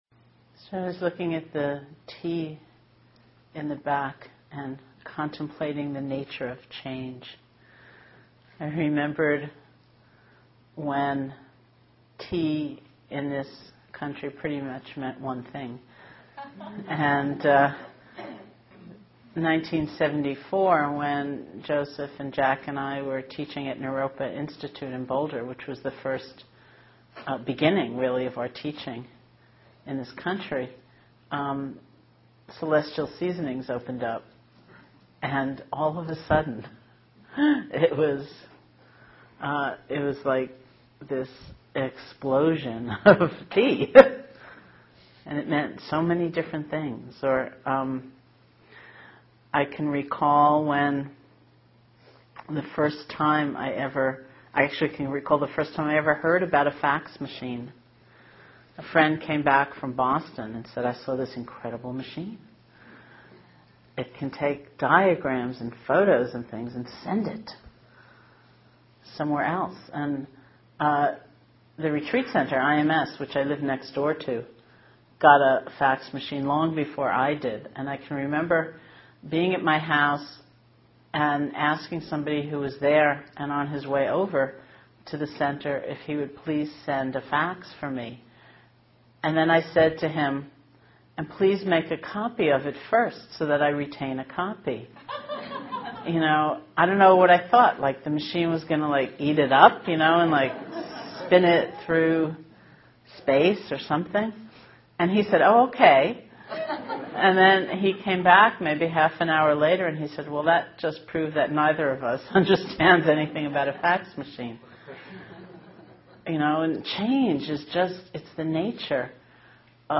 Teacher: Sharon Salzberg Date: 2005-07-09 Venue: Seattle Insight Meditation Center Series [display-posts] Description This talk was given on a weekend non-residential retreat with Sharon Salzberg in July 2005.